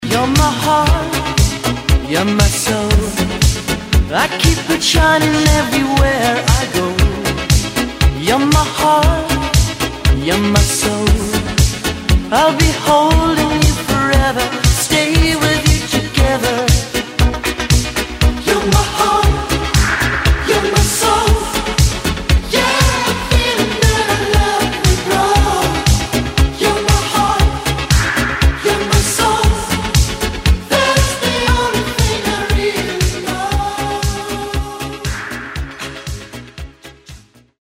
Романтический рингтон 2024